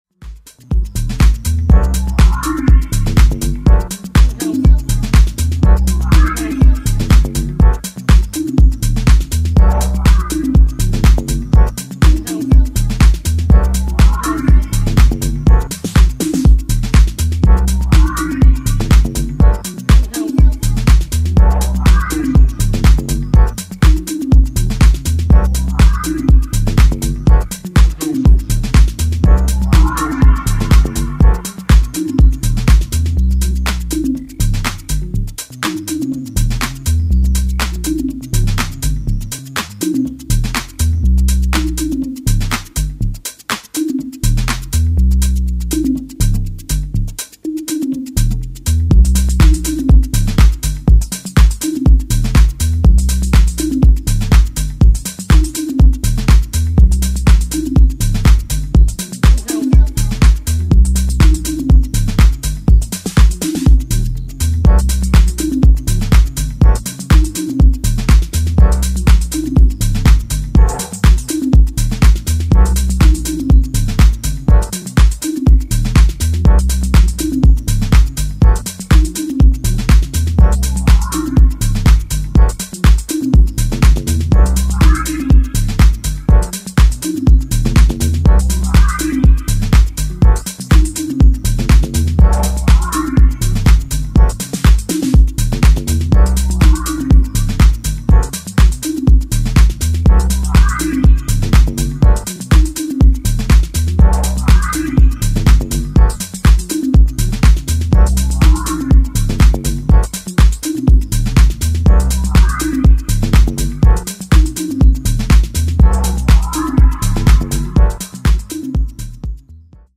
' a stomper aimed straight at the dancefloor.